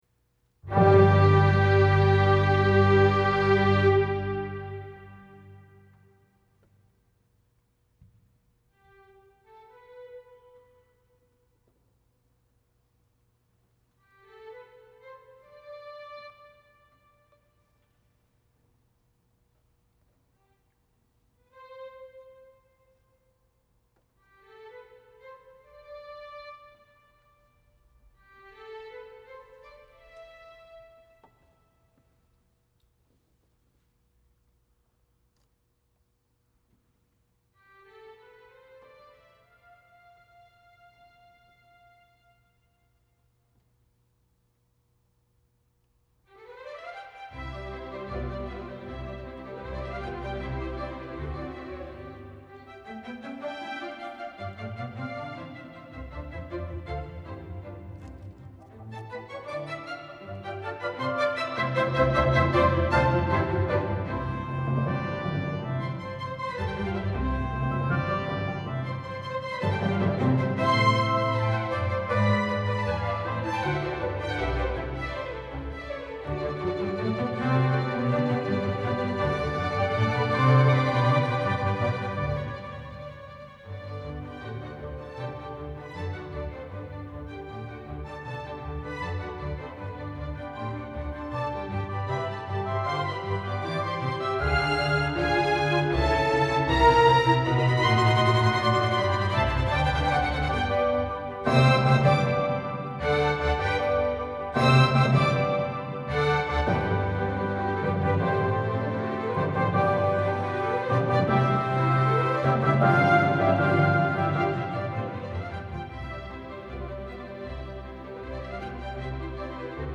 Live Concert with Vienna Instruments - Recordings | VSL Forum
NOTE: no reverb was used at all. What you hear is the natural acoustics of the hall.
The Fauxharmonic Orchestra